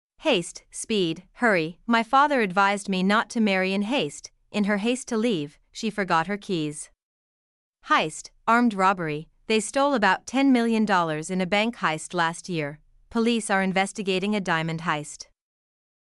haste vs. heist haste [heɪst] (speed; hurry) My father advised me not to marry in haste.